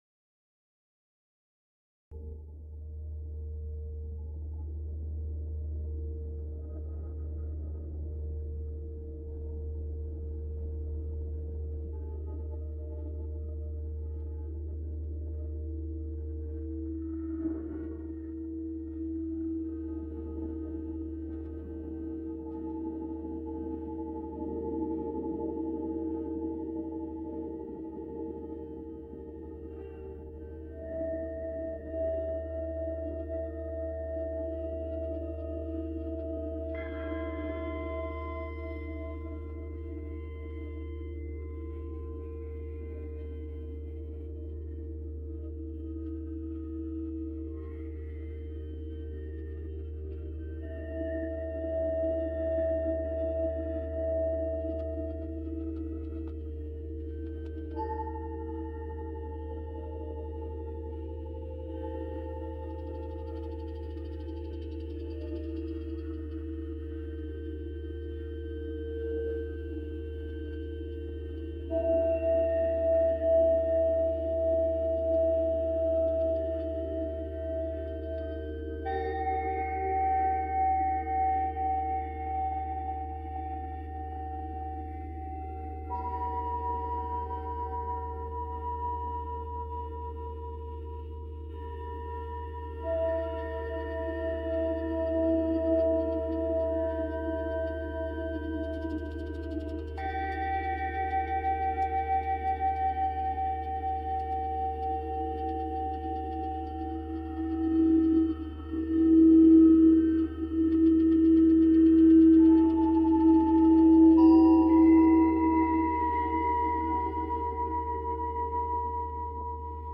🌅 138Hz Ambient 「光が、静けさをひとつ包み込む」 静かな映像と音で、心を整える時間を。